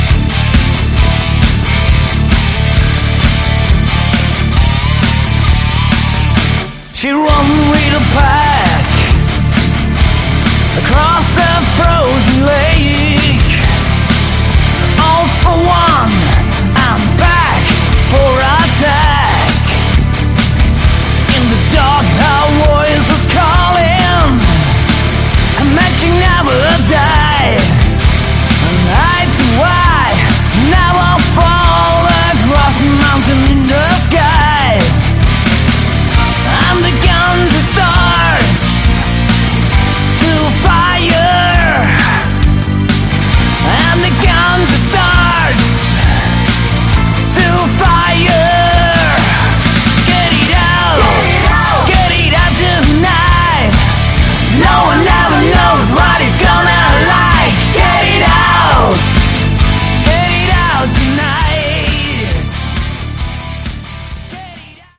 Category: melodic hard rock
Vocals
Guitars
Bass
Keyboards
Drums